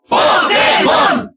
Category:Crowd cheers (SSBB)
Pokémon_Trainer_Cheer_Spanish_SSBB.ogg.mp3